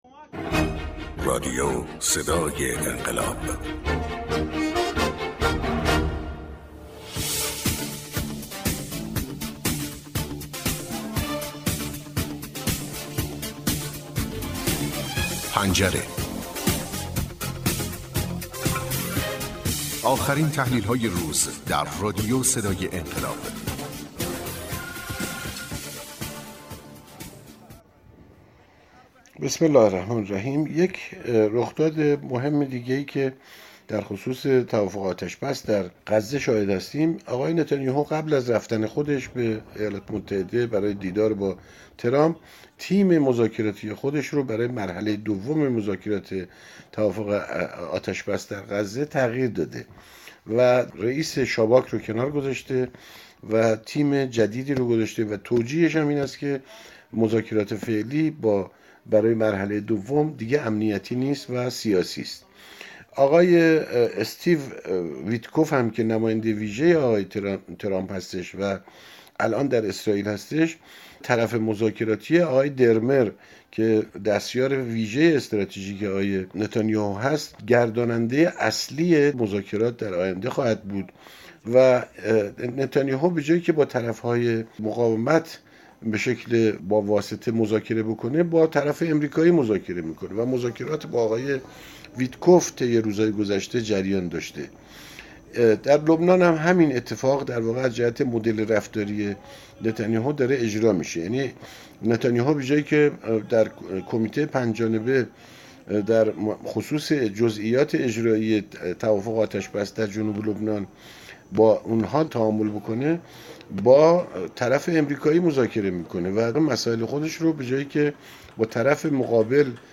تحلیلگر سیاسی و پژوهشگر منطقه و بین الملل